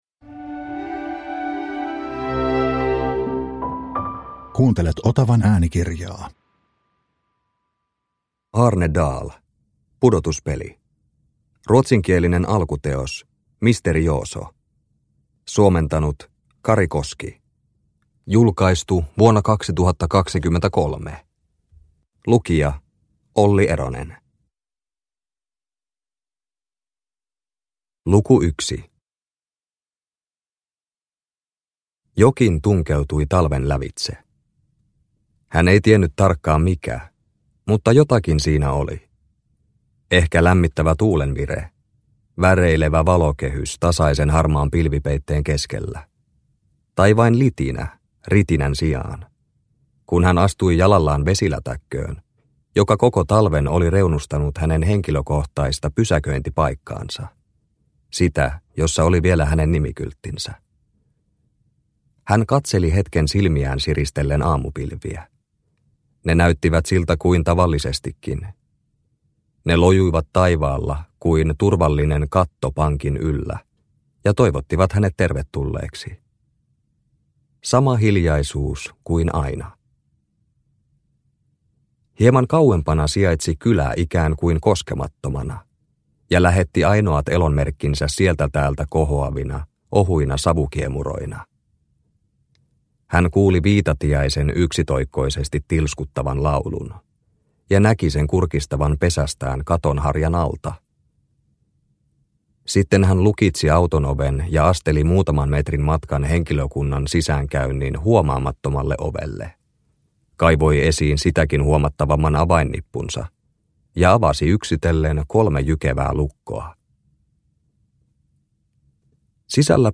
Pudotuspeli – Ljudbok – Laddas ner